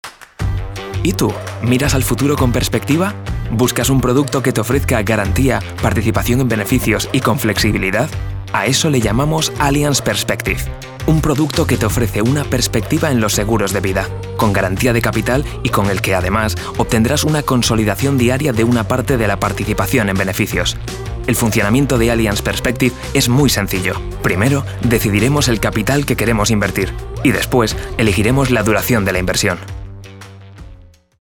Commercieel, Natuurlijk, Stedelijk, Stoer, Warm
Corporate